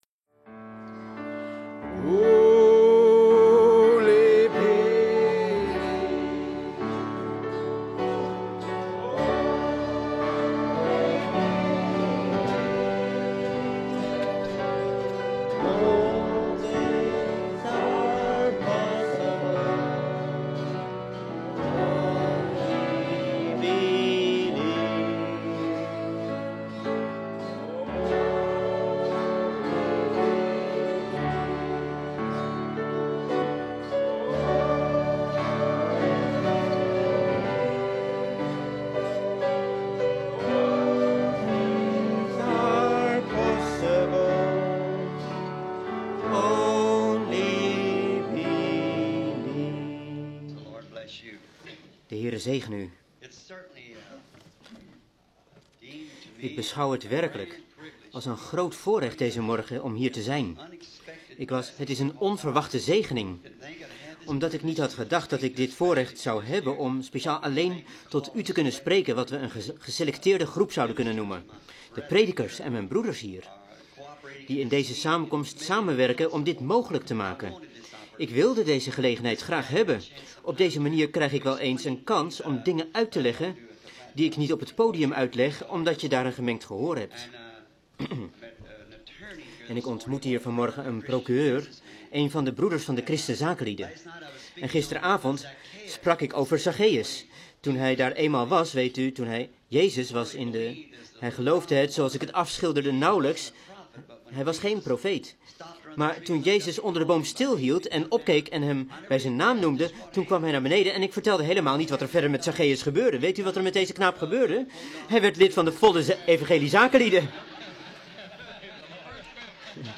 Vertaalde prediking "A paradox" door William Marrion Branham te Morrison's cafeteria, Tampa, Florida, USA, 's ochtends op zaterdag 18 april 1964